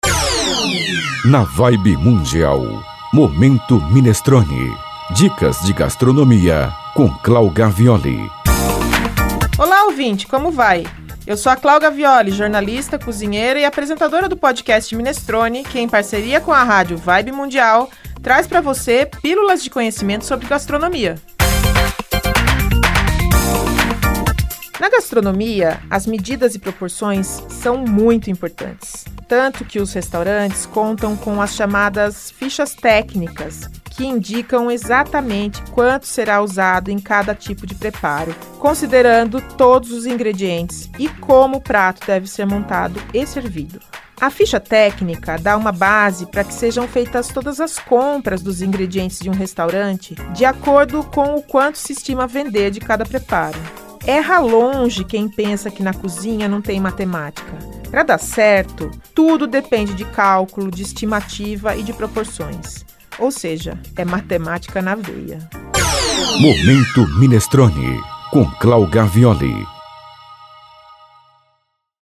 Três vezes ao dia, durante a programação da rádio (às 7h25, 14h25 e 22h25), são veiculadas pílulas de conhecimento em gastronomia.